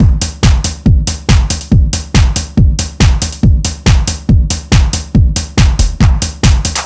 NRG 4 On The Floor 012.wav